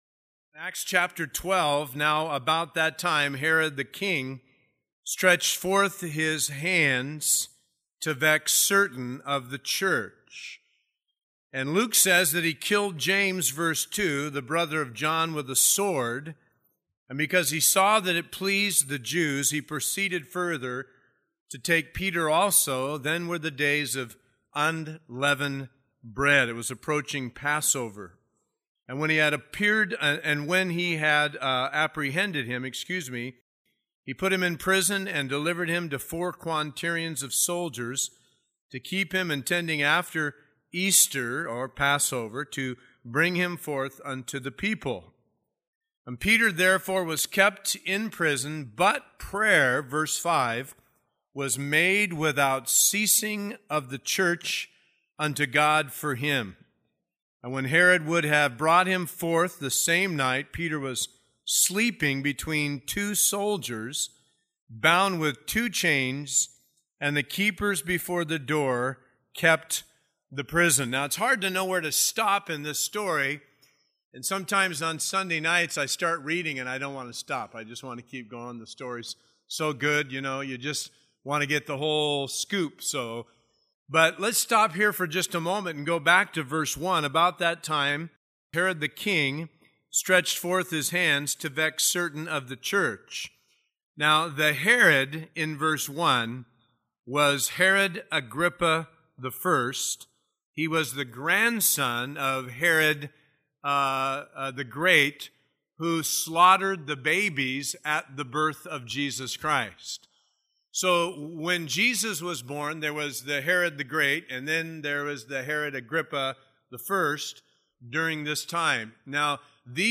taught at Calvary Chapel San Bernardino from June 2009 to January 2010.